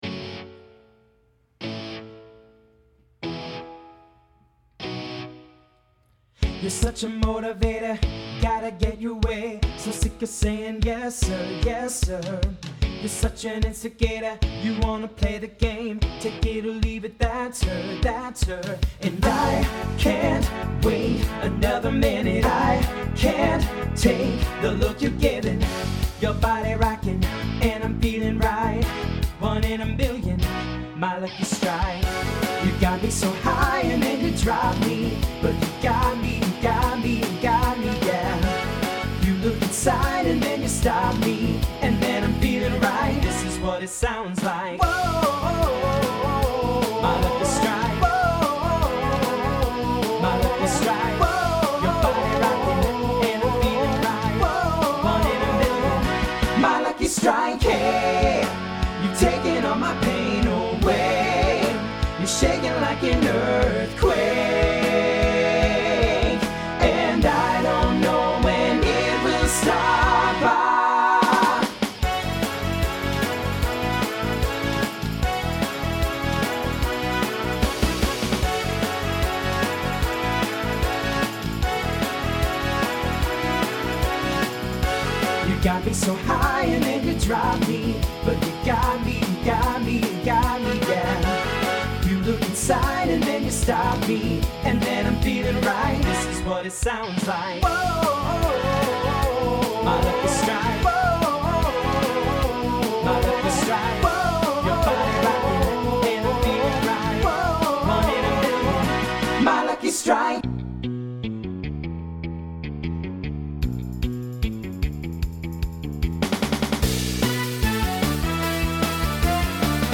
TTB/SSA